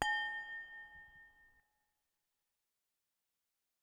next-round.wav